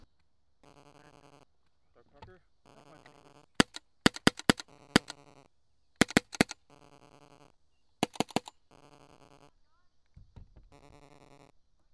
autococker_dry_raw_-3db.wav